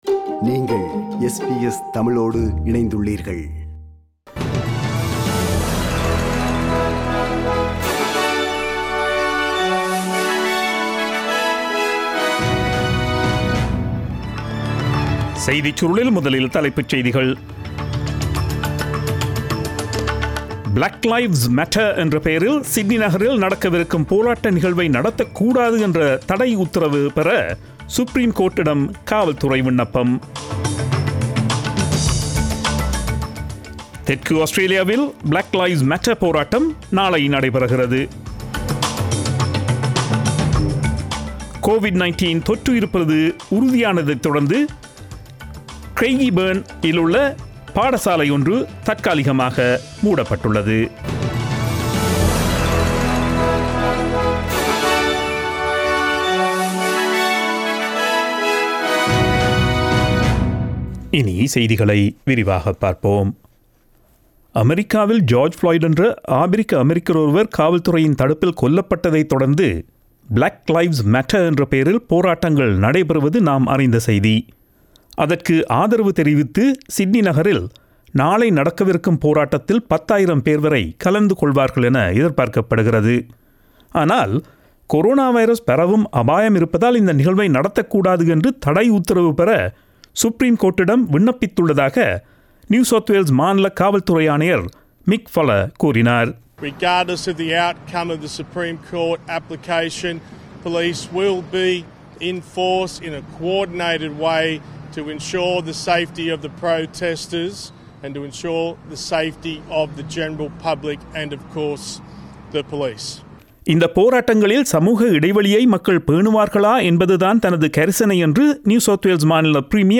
Australian news bulletin aired on Friday 05 June 2020 at 8pm.